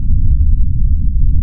engine-lowfreq.wav